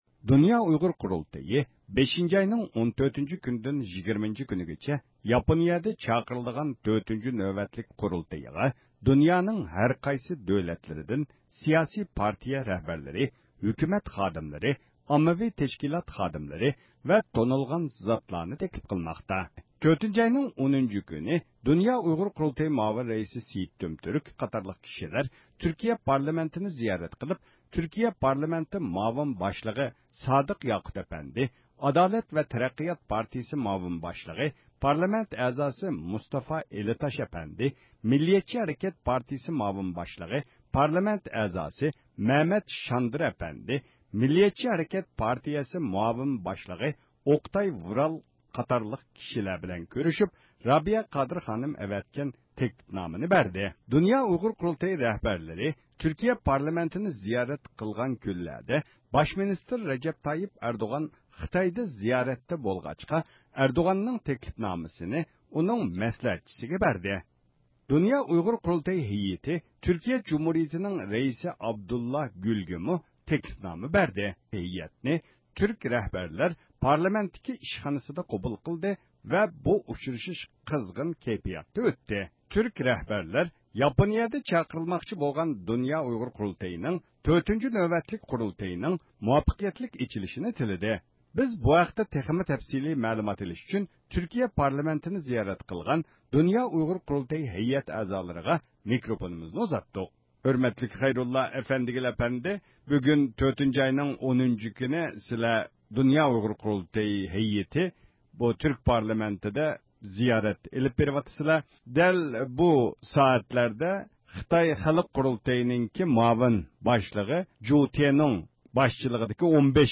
بىز بۇ ھەقتە تېخىمۇ تەپسىلىي مەلۇمات ئېلىش ئۈچۈن تۈركىيە پارلامېنتىنى زىيارەت قىلغان د ئۇ ق ھەيئەت ئەزالىرىغا مىكرافونىمىزنى ئۇزاتتۇق.